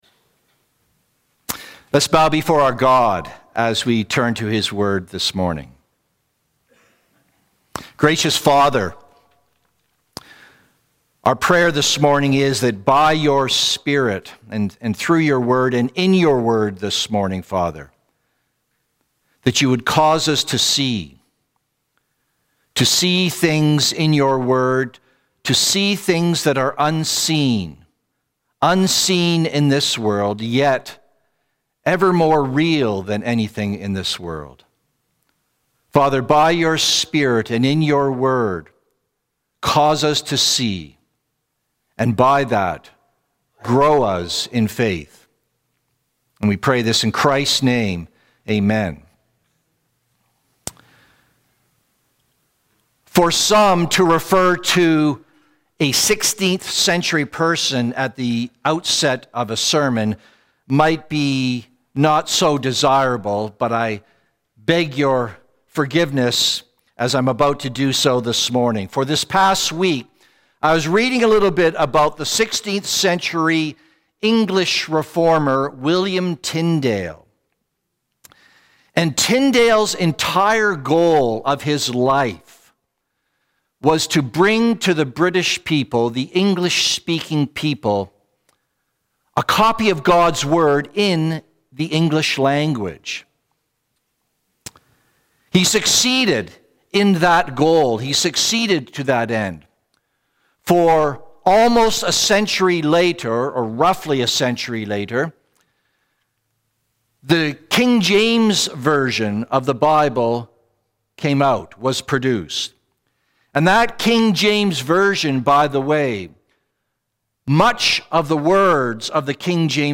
Sermons | Cranbrook Fellowship Baptist